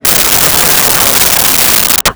Crowd Laughing 09
Crowd Laughing 09.wav